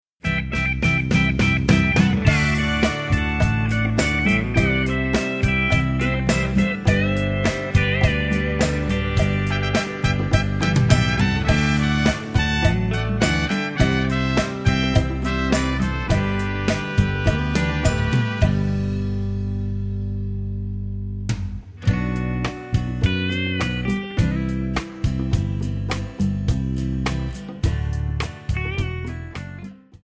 MPEG 1 Layer 3 (Stereo)
Backing track Karaoke
Pop, Ital/French/Span, 2000s